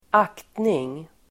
Uttal: [²'ak:tning]